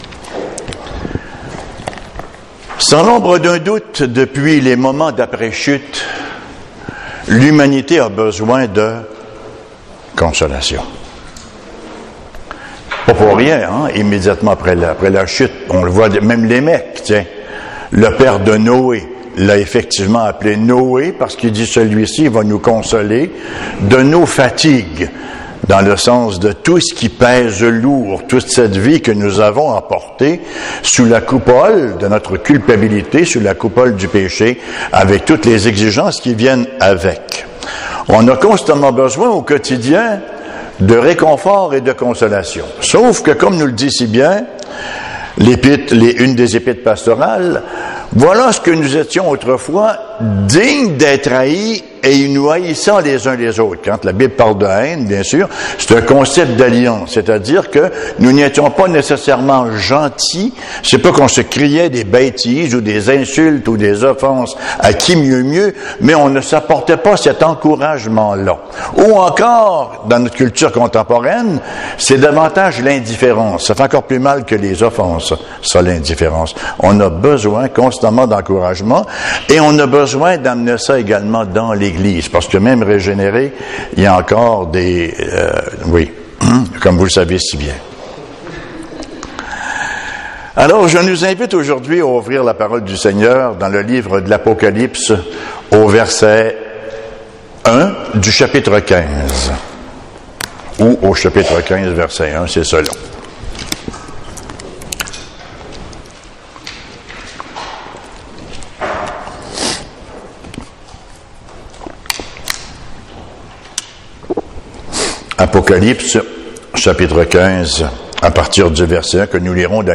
Serie de sermons